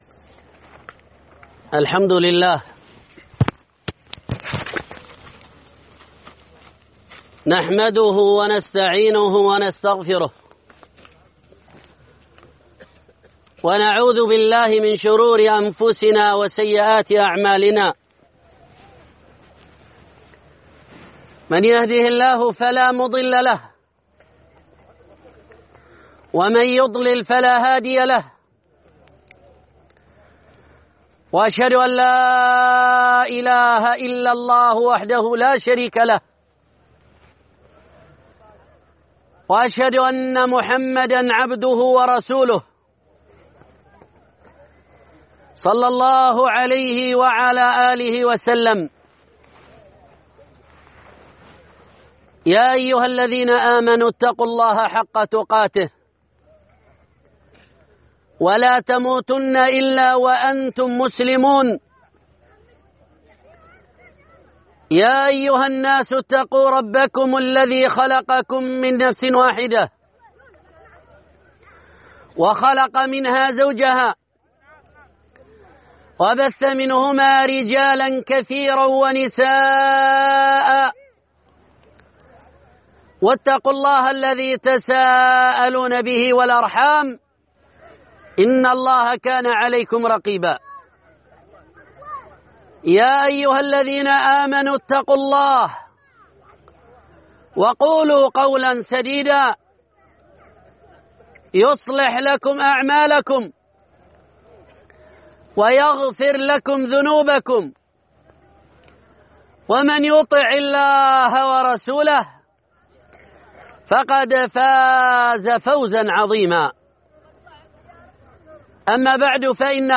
خطبة عيد الأضحى المبارك (2)